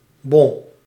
Nl-bon.ogg